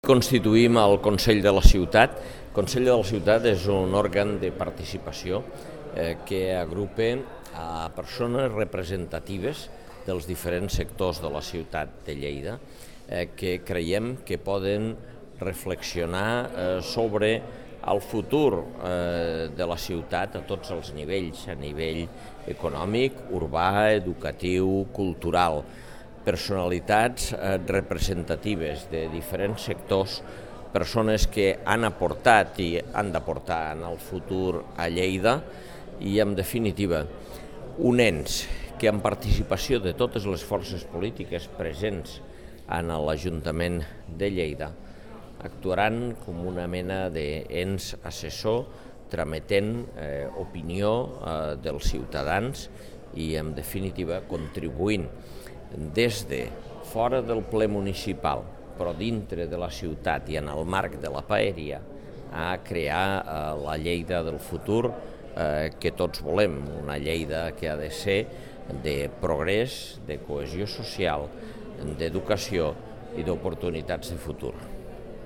Fitxers relacionats Tall de veu de l'alcalde de Lleida, Àngel Ros, sobre el Consell Assessor de la Ciutat de Lleida (881.9 KB) Imatge amb major resolució.